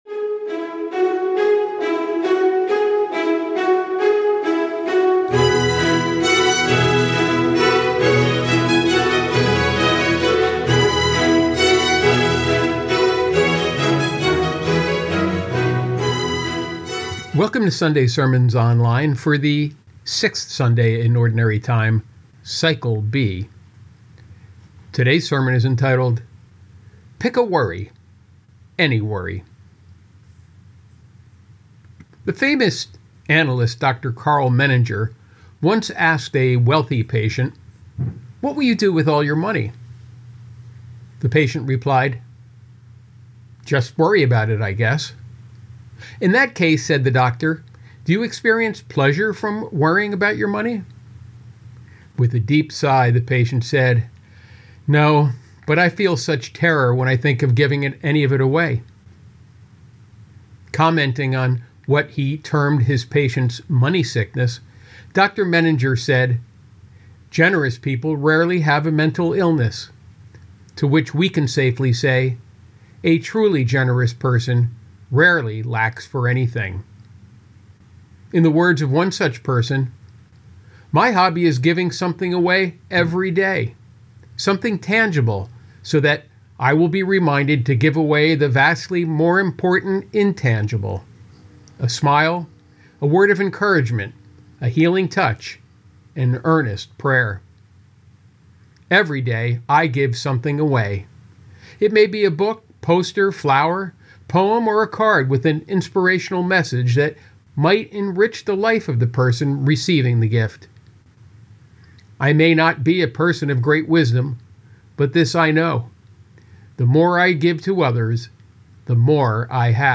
Sample Sunday Sermon